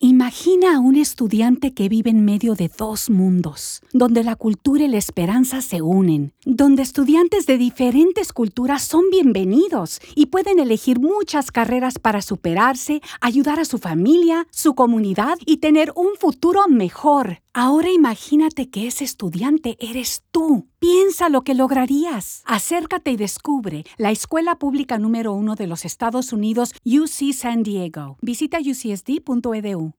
Anuncio de radio - Imagina